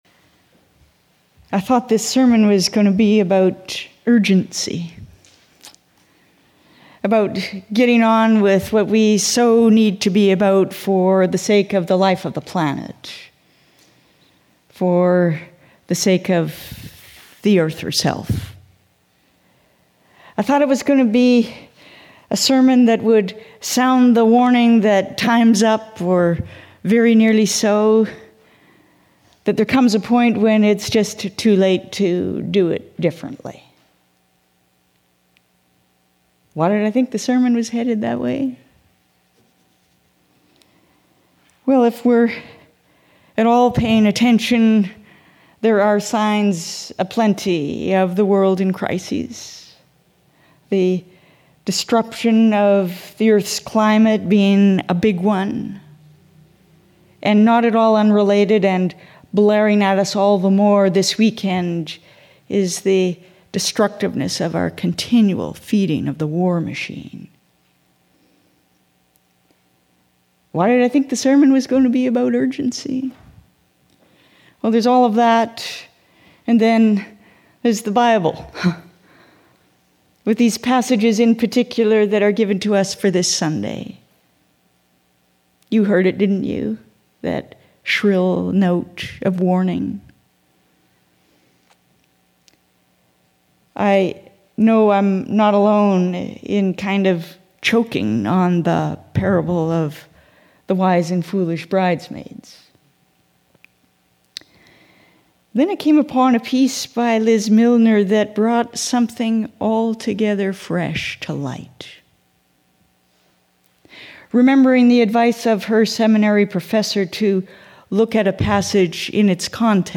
Sermons | James Bay United Church